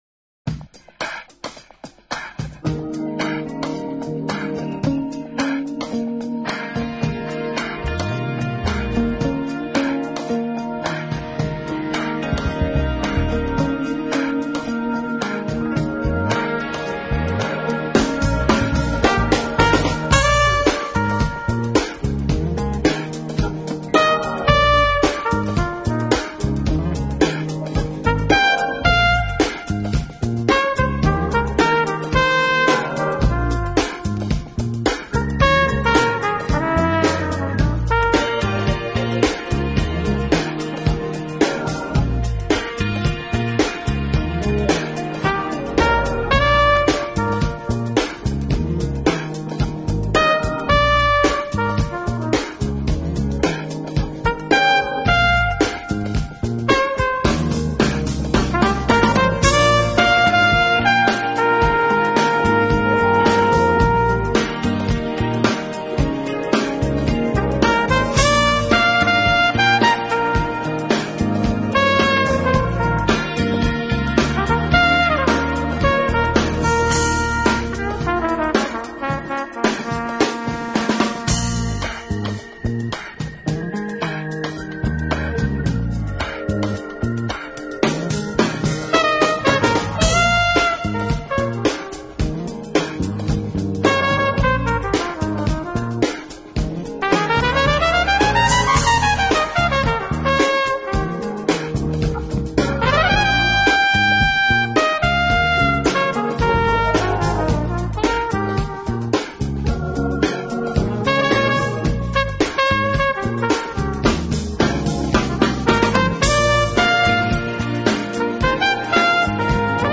小号演奏依旧锐利，稍带阴沉却丰富温暖的音色让整张专辑听起来十分让人放松。